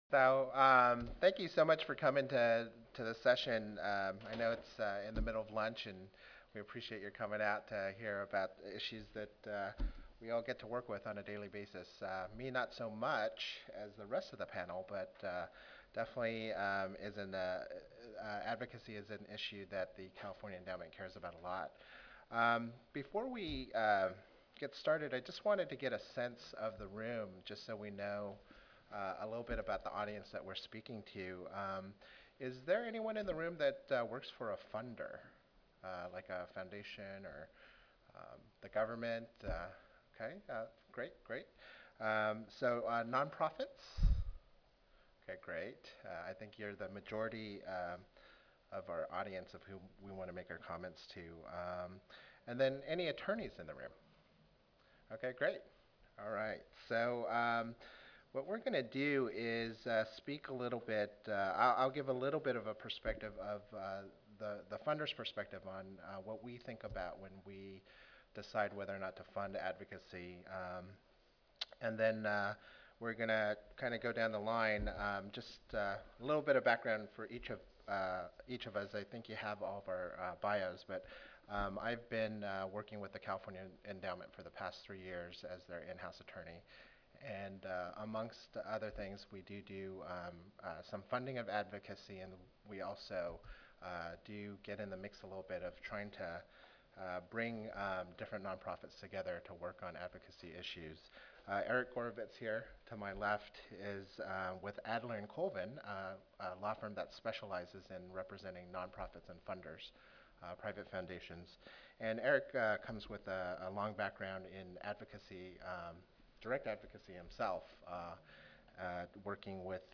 This session is designed to help advocates understand the legalities surrounding lobbying, education and advocacy in relation to their employment situation. Panelists will address advocacy laws and etiquette for employees in non-profit organizations, government agencies and other sensitive positions. Panelists also will discuss how the various non-profit tax statuses differ from each other and impact an organization�s advocacy or lobbying activities.